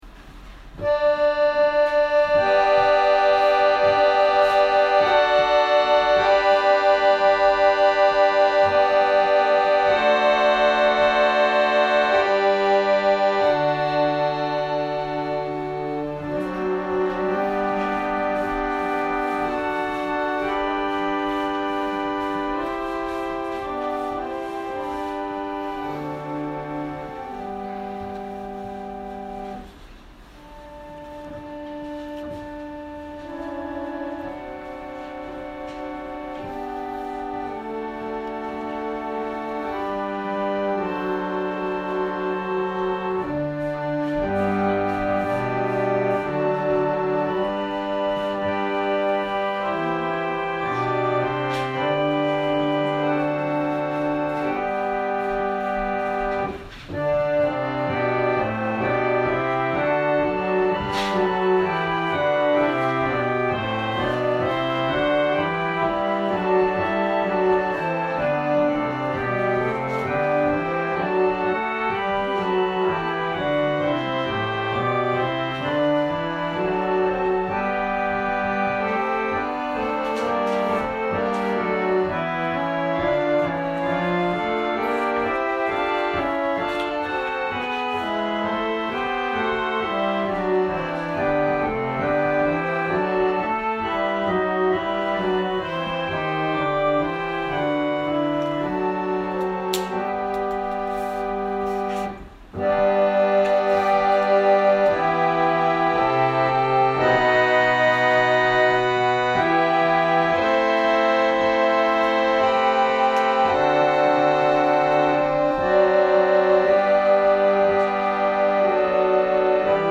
説教アーカイブ。
2023年は、元日に新年礼拝を迎えることが出来ました。